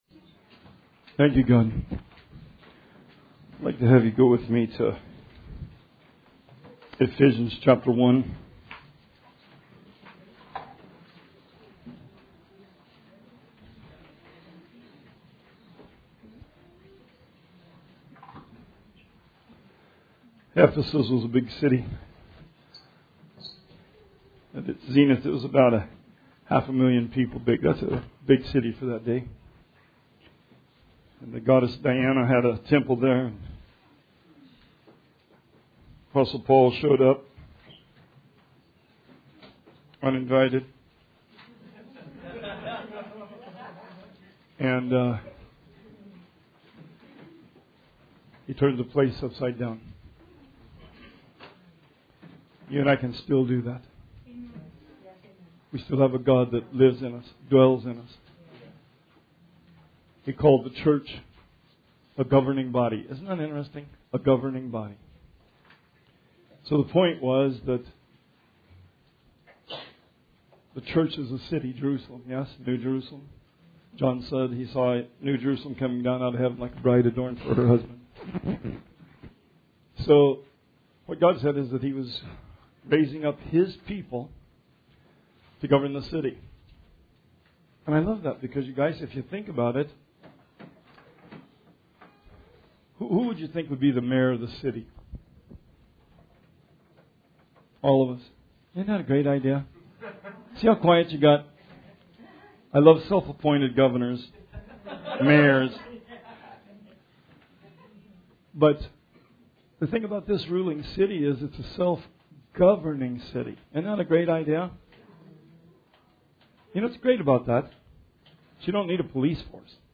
Sermon 7/7/19